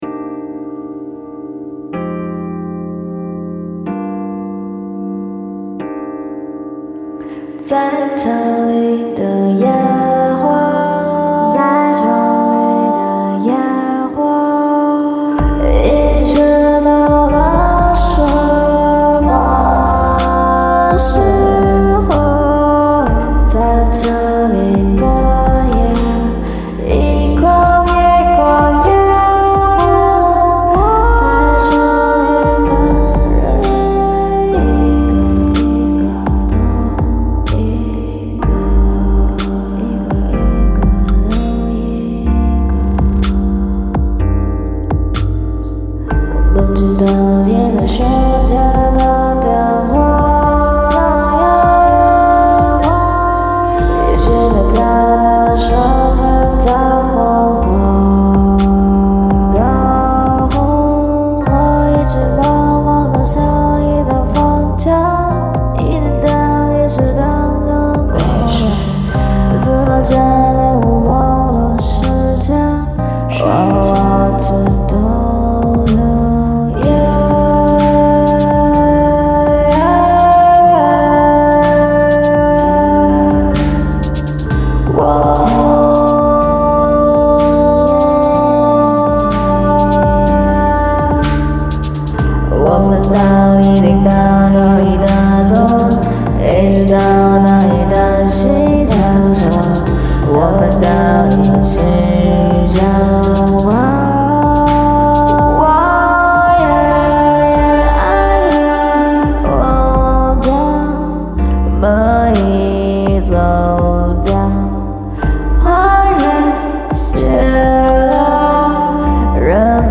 My music style varies from pop to indie to electronics.